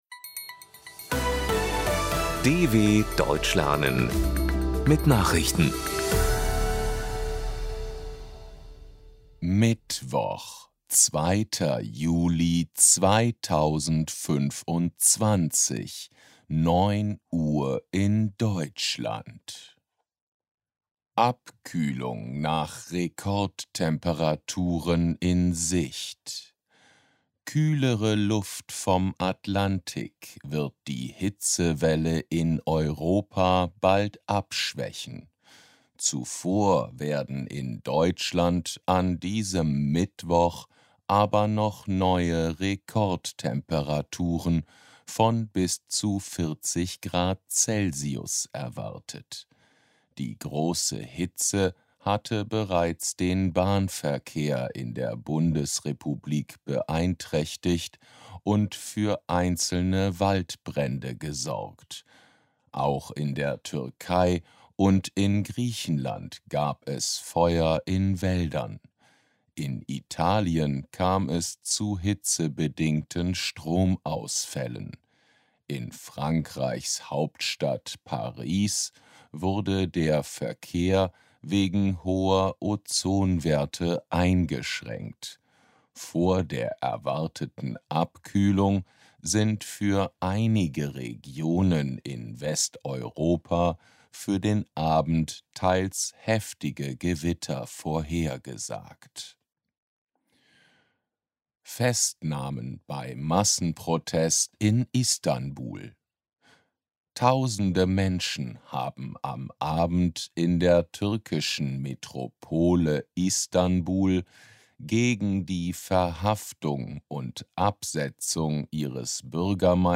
Langsam Gesprochene Nachrichten | Audios | DW Deutsch lernen
02.07.2025 – Langsam Gesprochene Nachrichten